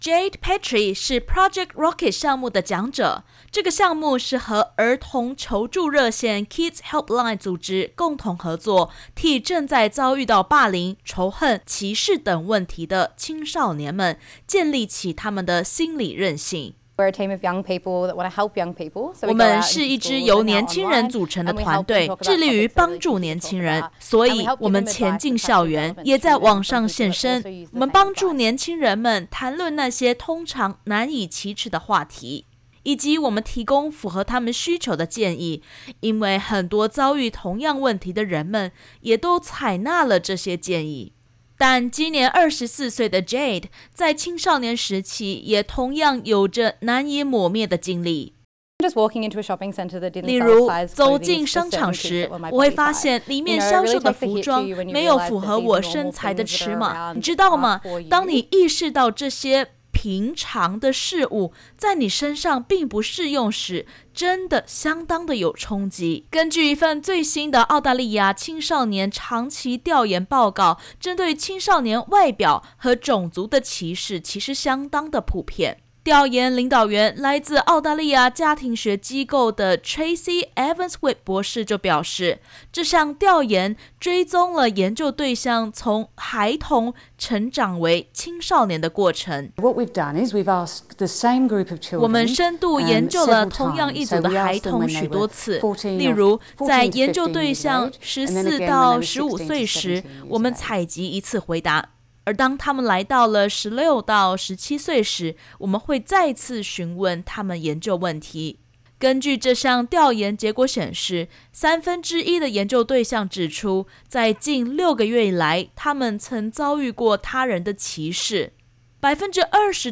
最新一份“澳大利亚孩童长期调研”指出，三分之一的澳大利亚青少年正在遭遇歧视，常见的歧视类别与他们的身材、外表或种族有关。点击首图收听完整音频报导。